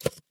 Звуки пенопласта